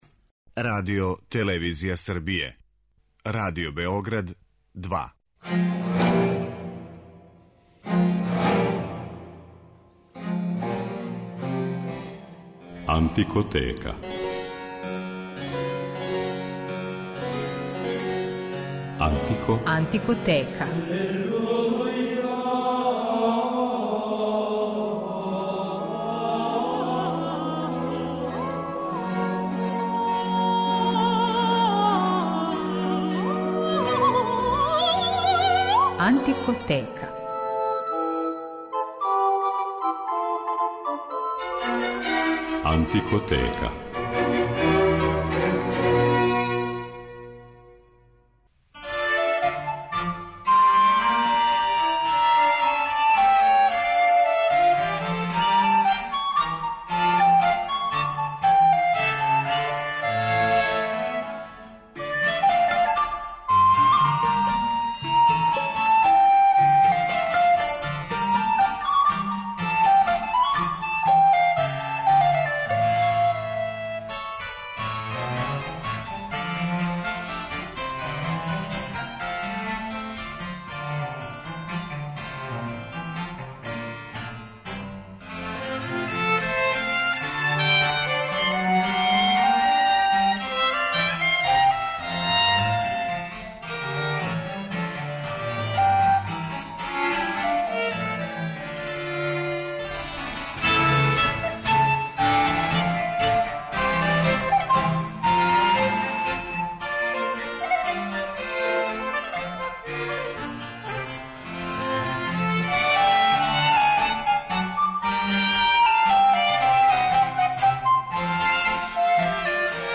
Реч је о британском барокном квартету Red priest, који не само да другачије од свих доживљава и свира музику епохе којој се посветио него као да је поново ствара својим екстравагантним интерпретацијама са много слободних импровизација.
Овом изузетном ансамблу је посвећена прва Антикотека у новој години, и у њој ће нам барокни квартет Црвени свештеник изводити музику Антонија Вивалдија, Герога Филипа Телемана и Франсоа Купрена. У рубрици Антикоскоп, остајемо у Великој Британији и квартетској музици и представљамо изванредне младе солисткиње на многобројним врстама блок флауте, чланице квартета Палисандер .